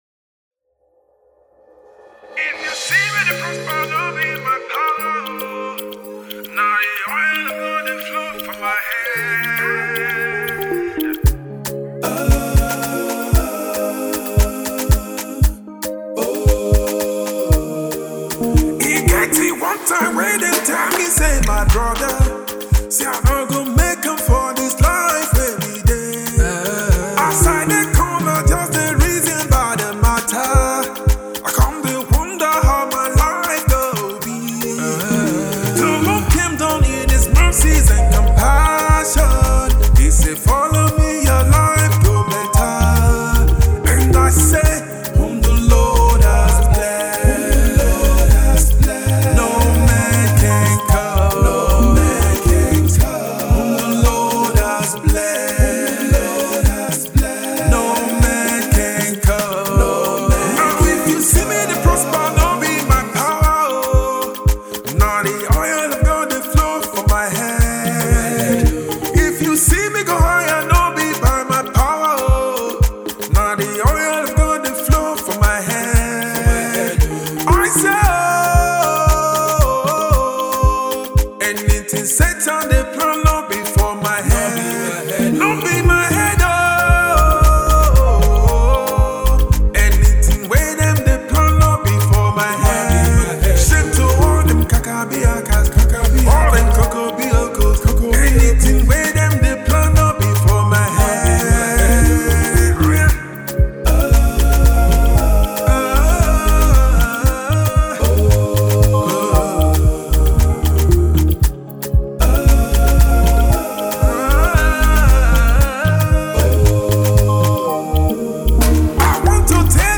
Prolific Gospel music minister